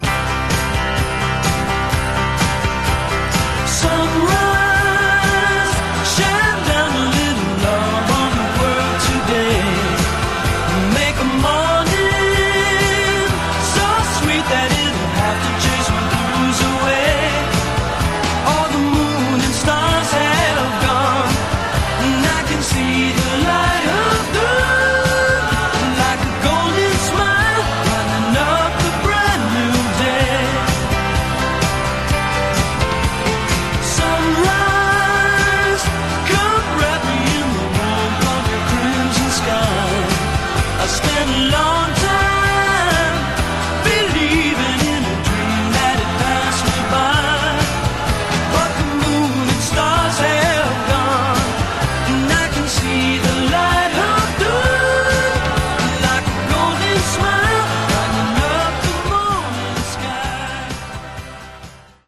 Genre: Sunshine Pop